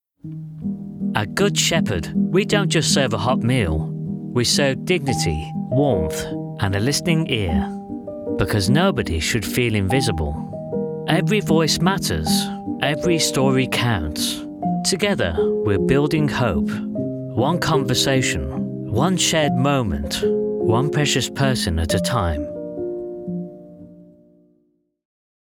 A dynamic British male voice. Positive, friendly and sincere with subtle Midlands tones.
Radio Commercials
1106Ad-Good_Shephard.mp3